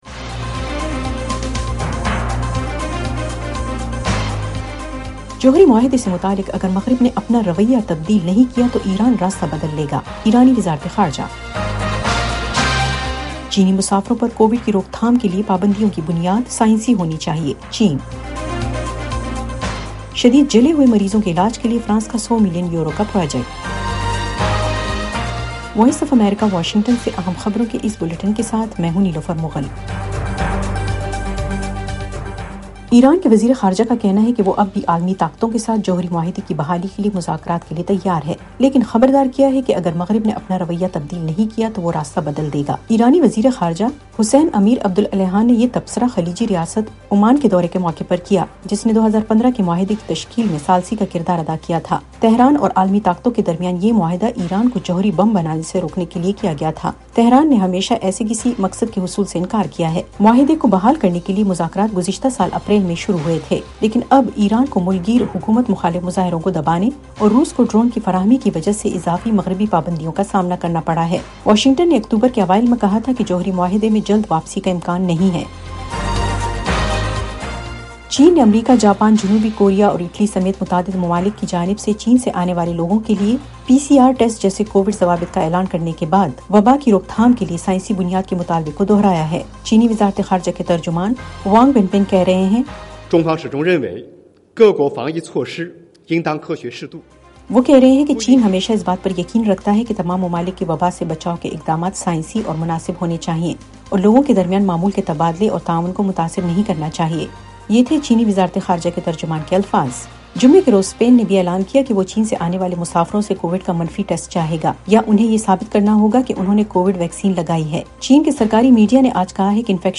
ایف ایم ریڈیو نیوز بلیٹن : رات 10 بجے